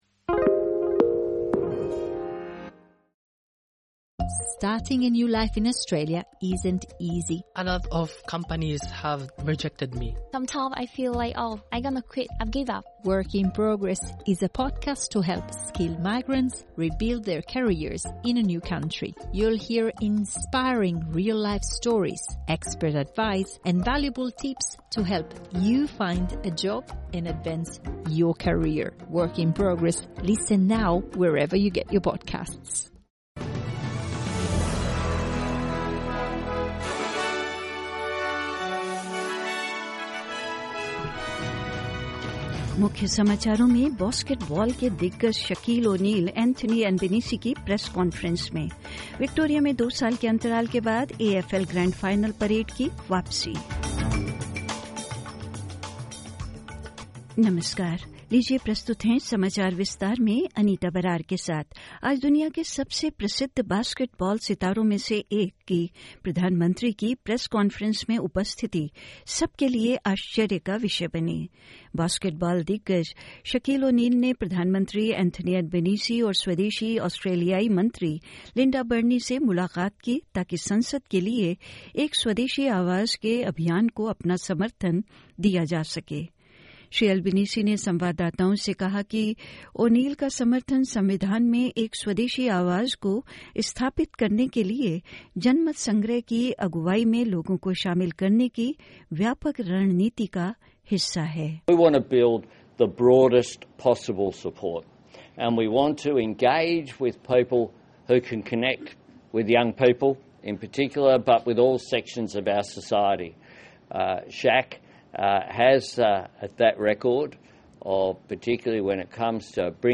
In this latest SBS Hindi bulletin: In this bulletin: Basketball legend Shaquille O'Neal appears at Anthony Albanese's press conference; Victoria bringing back the A-F-L grand final parade after a two year hiatus and more news.